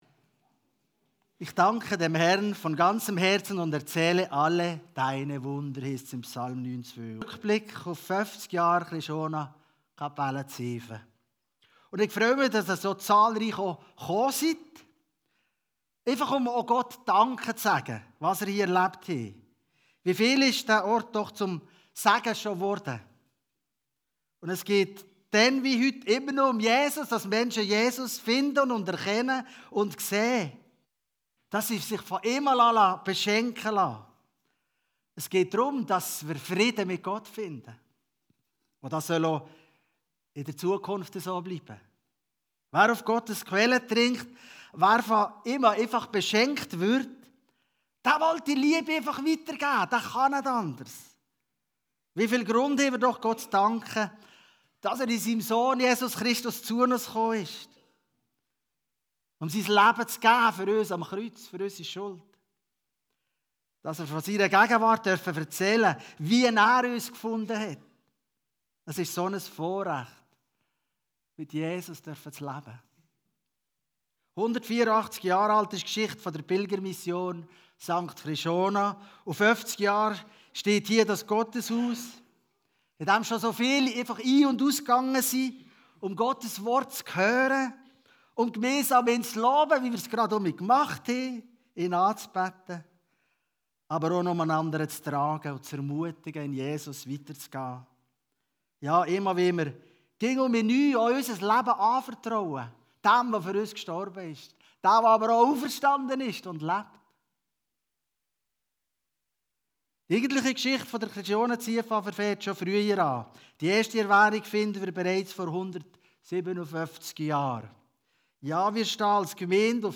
Jubiläumspredigt 50 Jahre Kapelle Ziefen › Chrischona Fünflibertal